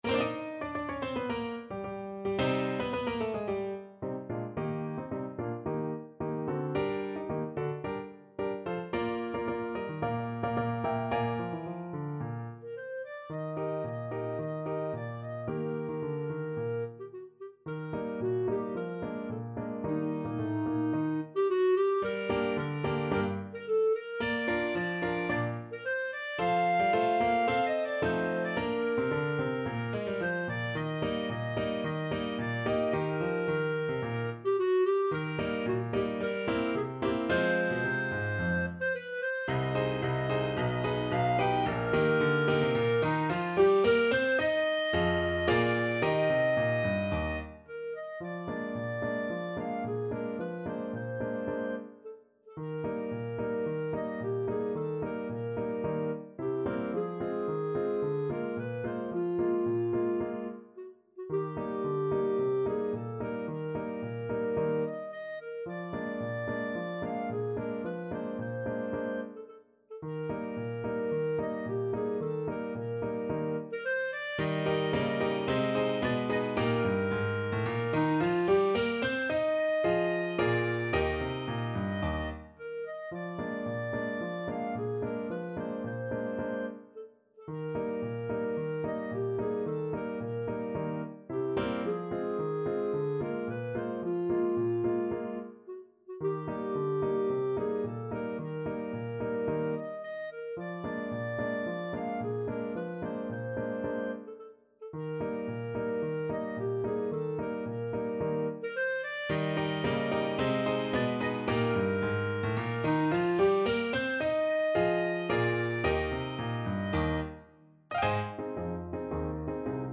Clarinet
Eb major (Sounding Pitch) F major (Clarinet in Bb) (View more Eb major Music for Clarinet )
2/2 (View more 2/2 Music)
Classical (View more Classical Clarinet Music)